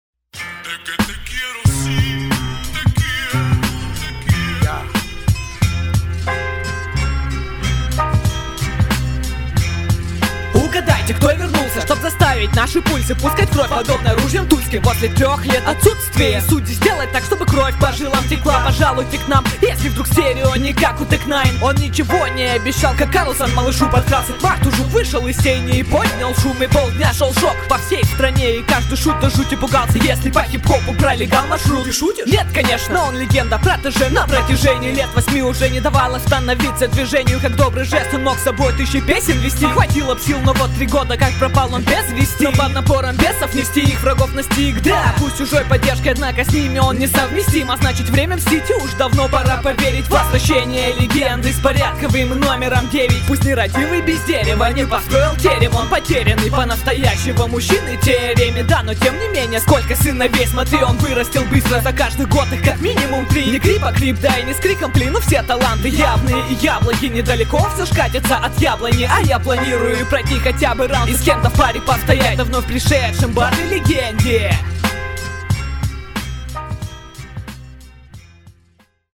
Трек на лучший хип-хоп баттл в России , 9 оффициальный хип-хоп.ру !